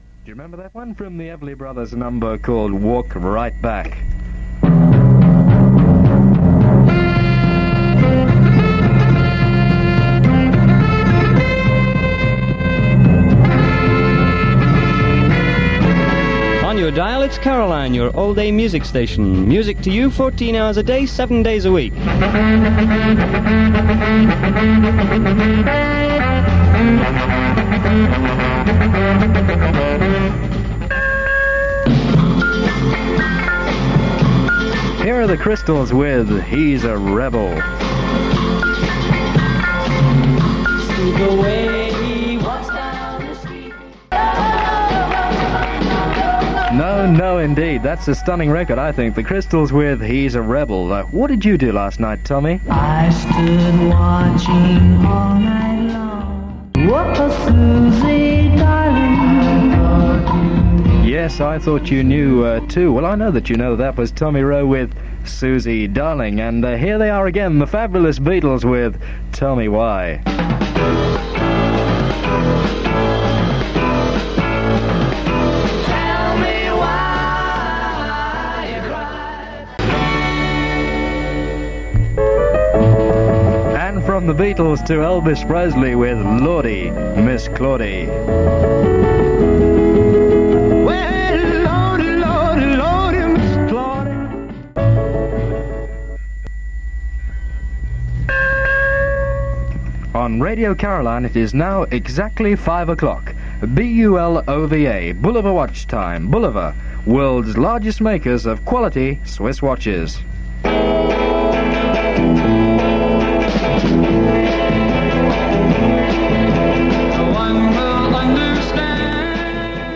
Radio Caroline South and Radio London's ships were anchored just off the coast of Clacton so the audio quality of the recordings from these stations is superb.
It features Tony Blackburn on The Big Line-Up programme.